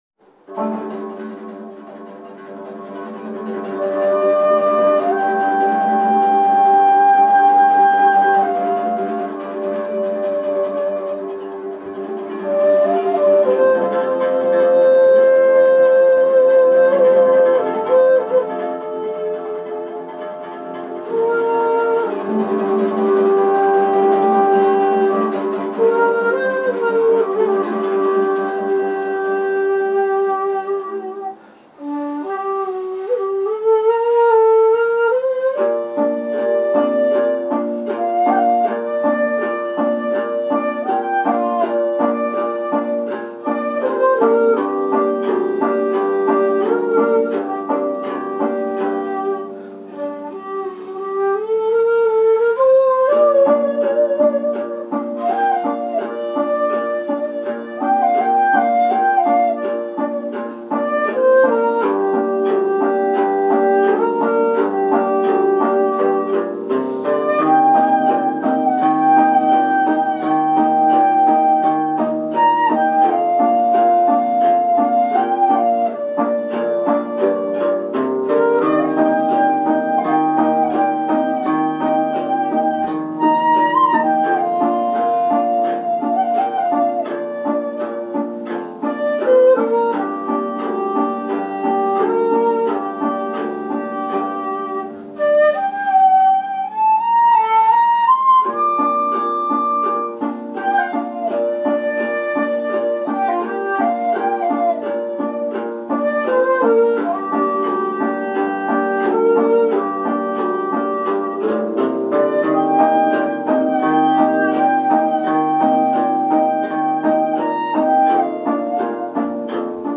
美しい響きの十七弦をバックに尺八の音まで何か伸びやかに聞こえます．